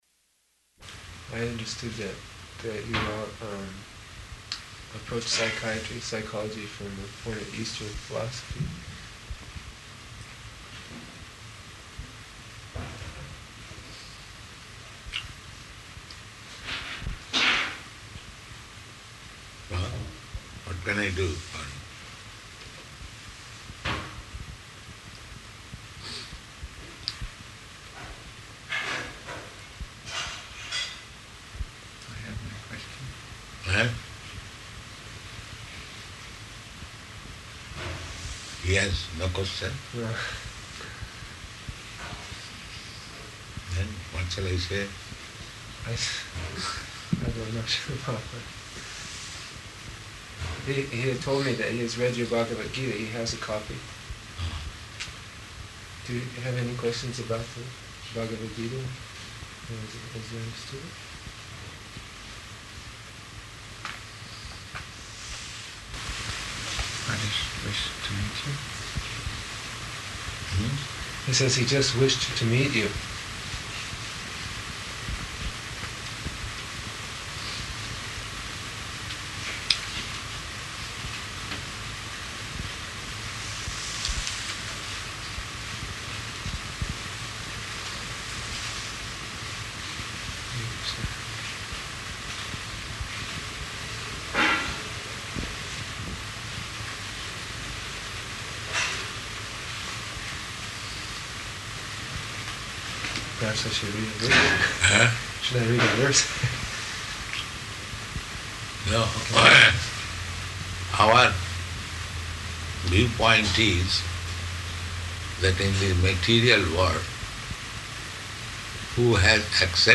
Room Coversation with Psychiatrist and Indian Boy
Type: Conversation